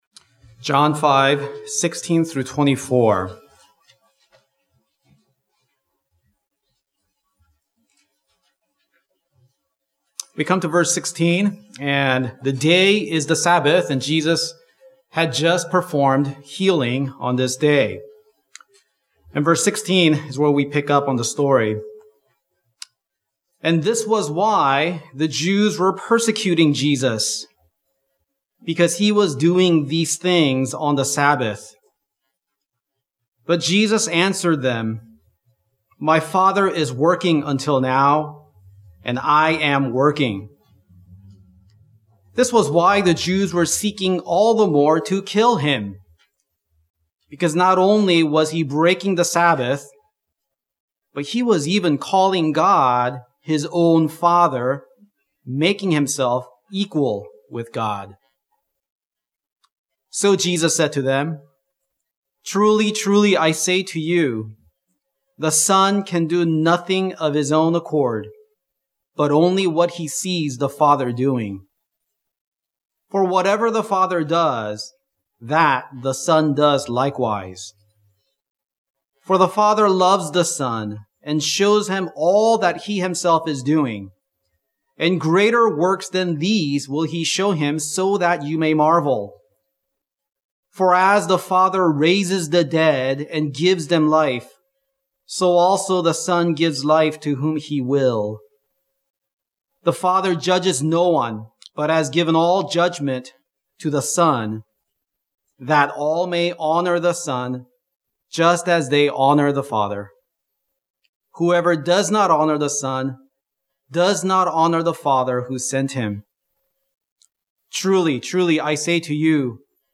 Jesus’ Explanation of His Divine Sonship Guest Preacher